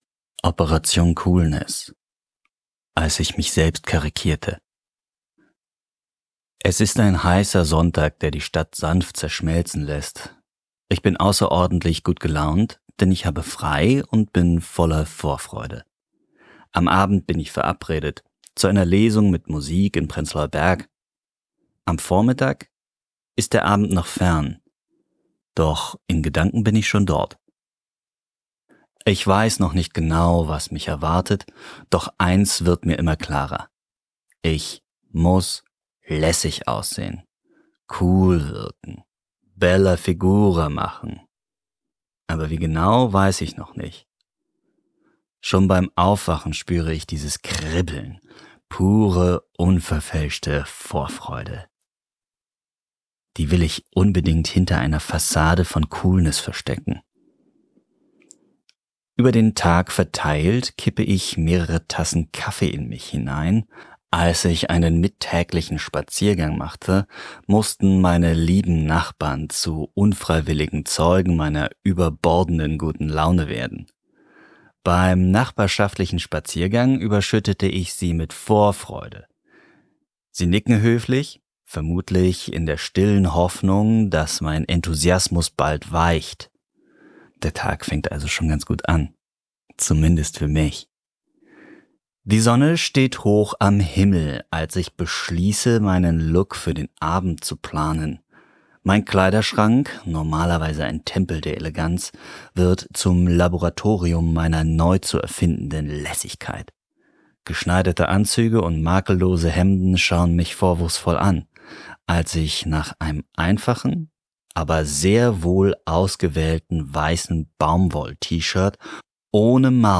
Lesungen und Hörbuch aus Berlin